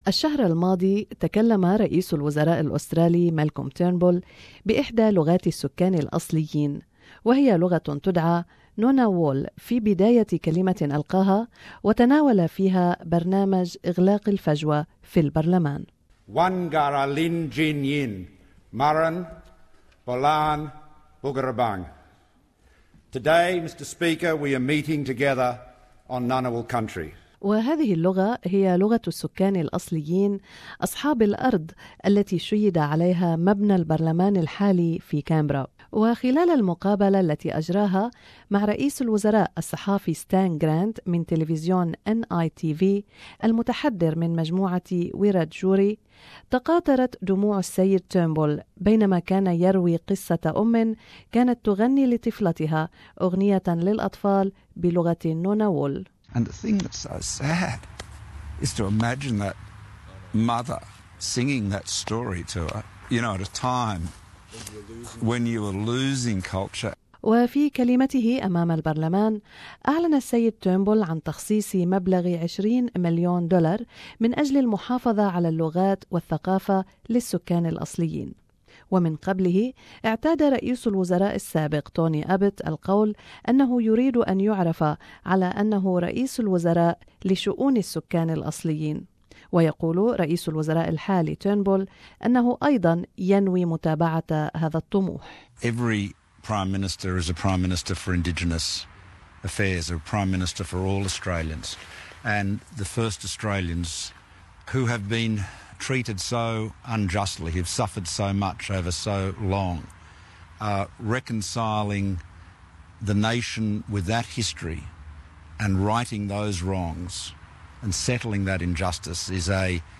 Prime Minister Malcolm Turnbull has been moved to tears in an interview with National Indigenous Television covering a wide range of Indigenous issues. Mr Turnbull offered his views on such areas as the high incarceration rates among Indigenous people and a potential referendum on constitutional recognition.